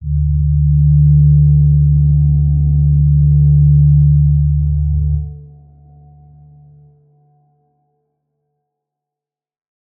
G_Crystal-D3-pp.wav